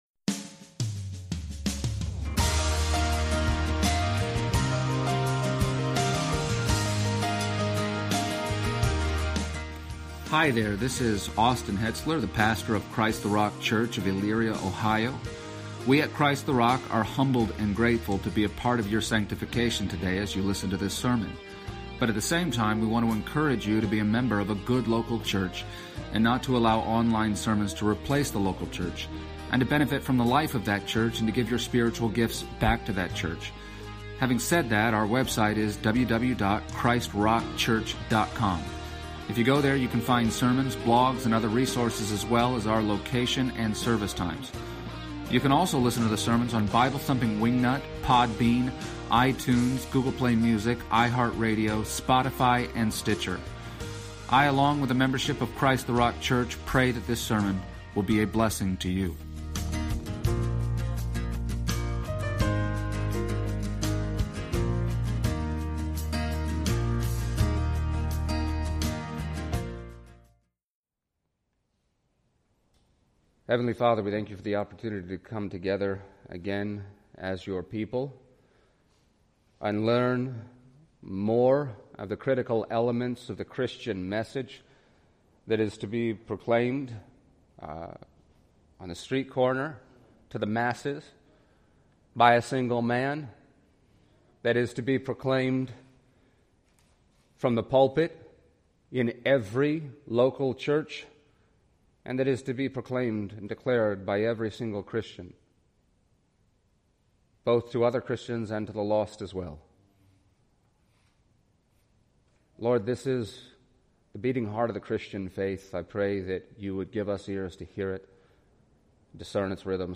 Passage: Acts 2:14-42 Service Type: Sunday Morning